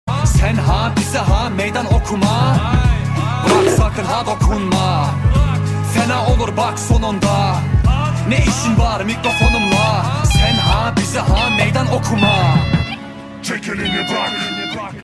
ELEKTRONİK BİSİKLET KORNASI YAPIMI. MAKE sound effects free download